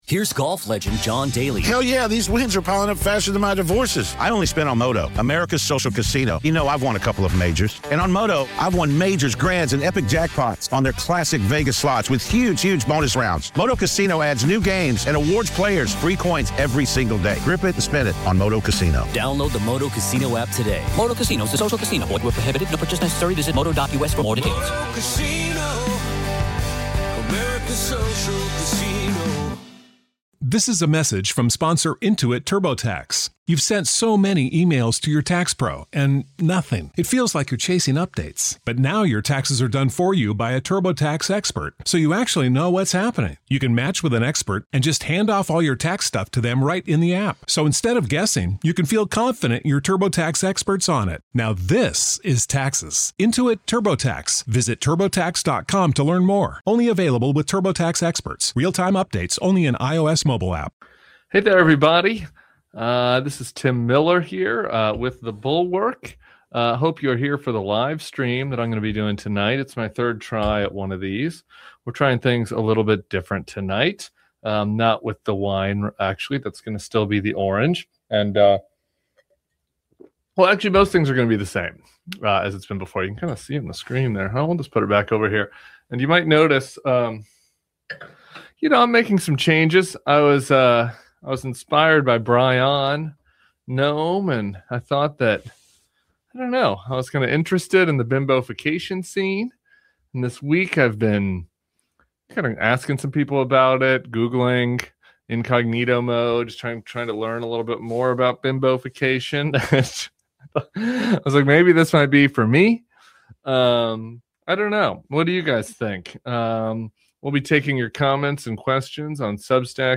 LIVE: Tim is Getting Bimbofied
Tim Miller went LIVE to take on a Trump world that’s starting to crack.